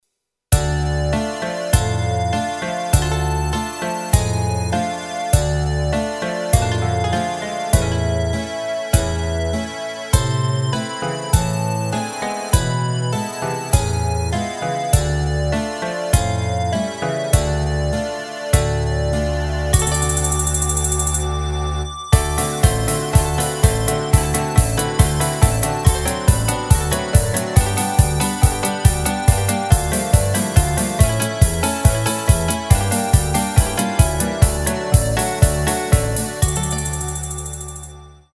Rubrika: Národní, lidové, dechovka
2/4  Andante